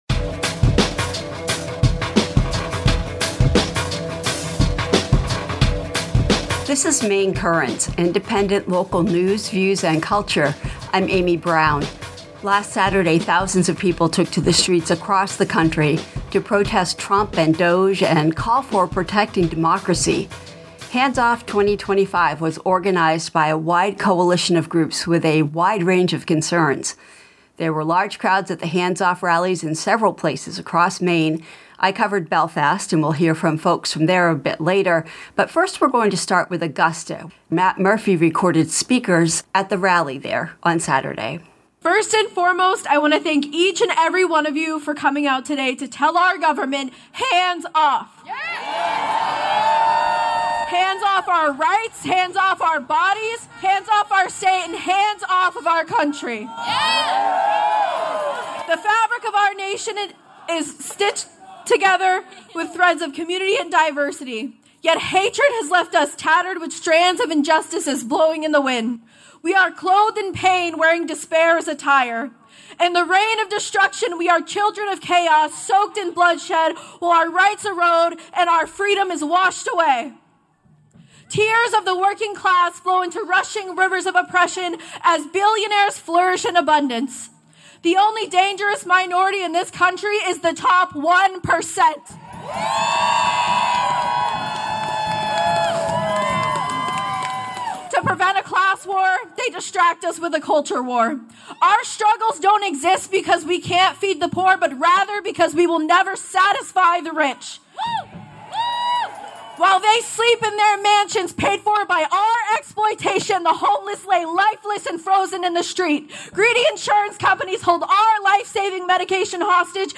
A group of folks involved with the exhibition sat down recently to talk about what went into putting it together, the connections with Donna’s book, and how decolonization efforts at the museum are going.